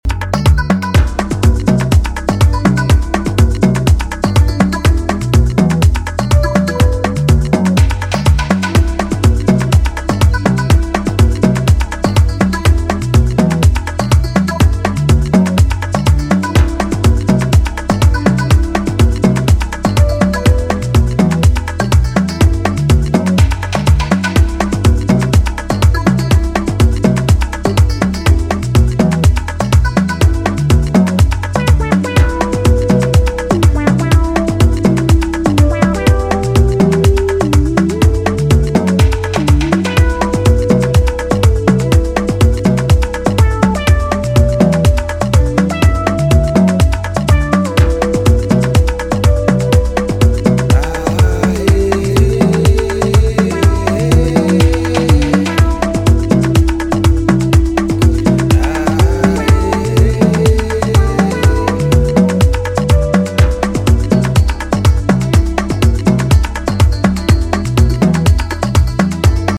two tracks that will work dance floors in equal measure